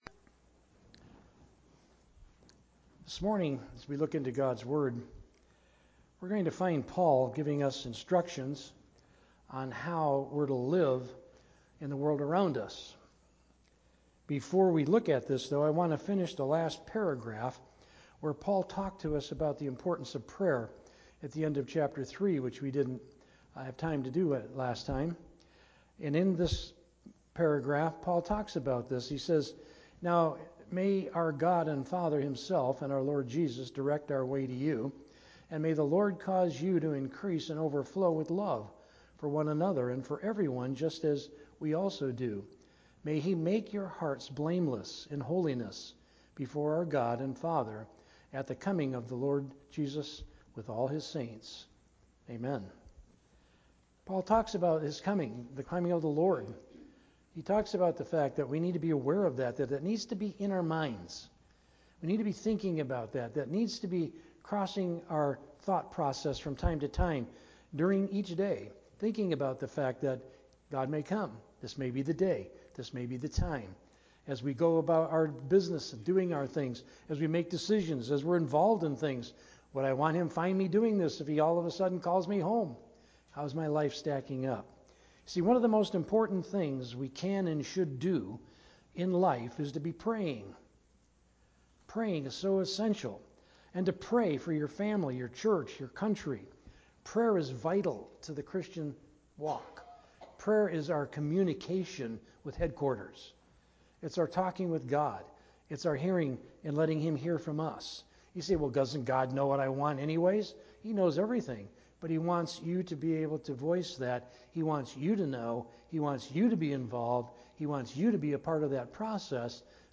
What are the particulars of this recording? From Series: "Sunday Morning - 11:00"